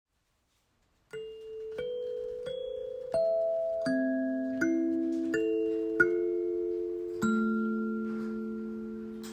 Sansula Deluxe
• Accord: la', do ", do ', la ', la, fa ', mi ', mi ", si '
• Tonalité: la mineur
• tonalité douce, avec un long sustain, facile à jouer
Cela donne un son merveilleux, doux et plein d'harmoniques que l'on peut facilement extraire de l'instrument.
L'ensemble de neuf dents en métal produit des sons harmonieux qui apportent une chaleur exceptionnelle à tout mixage.